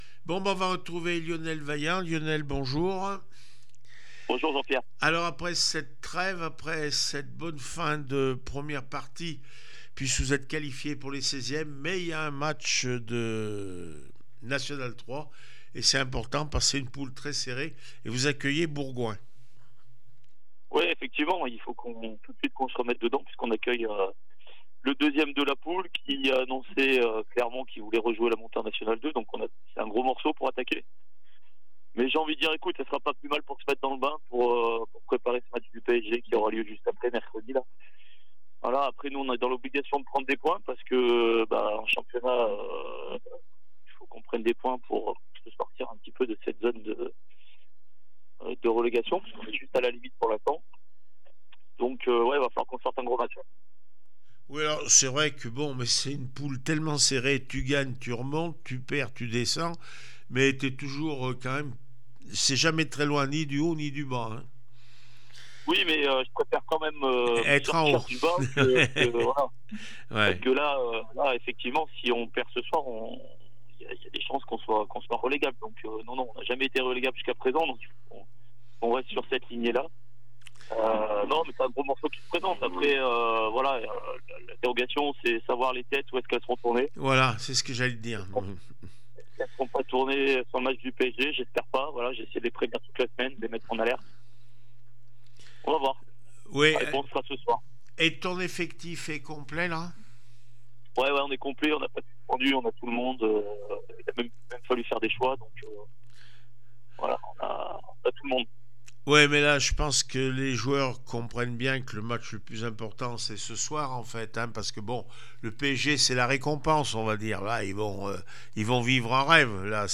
11 janvier 2025   1 - Sport, 1 - Vos interviews